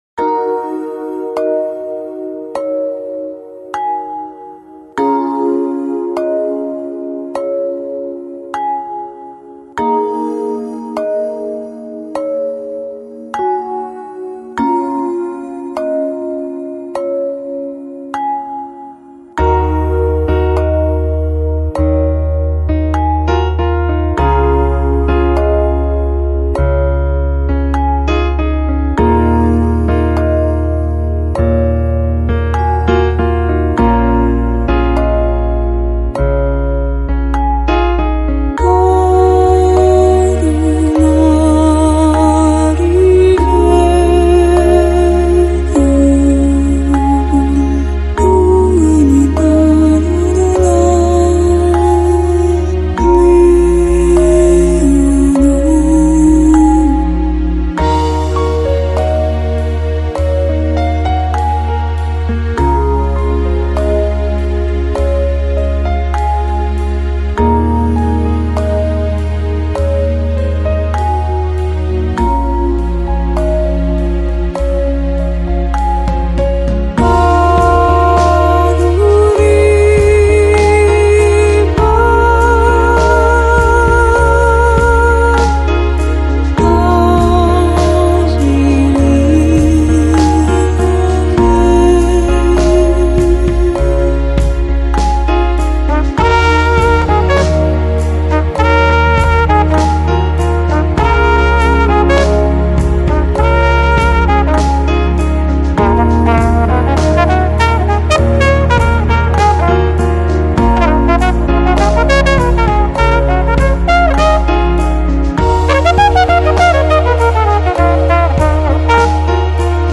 Lounge, Chillout, Downtempo, Soulful House Носитель